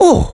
Below lies a collection of voice clips and sound effects from the first in the Mario Galaxy series!